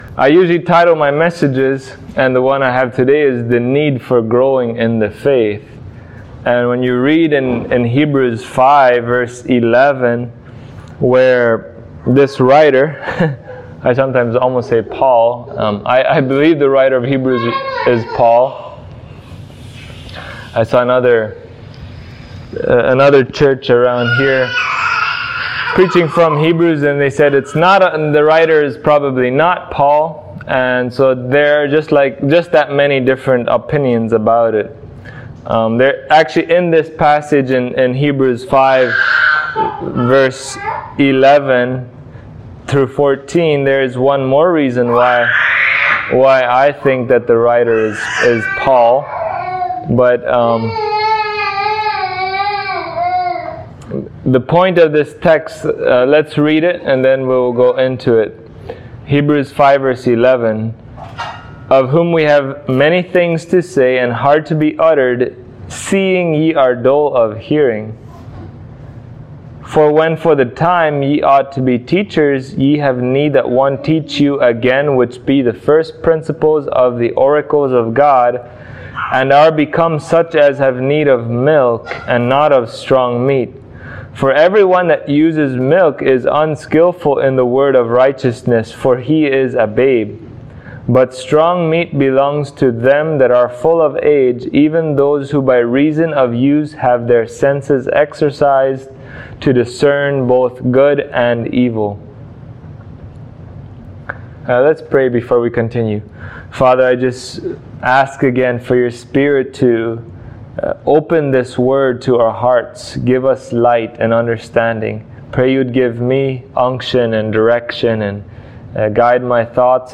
Hebrews 5:10-14 Service Type: Sunday Morning Why do we need to grow in grace and become mature as Believers?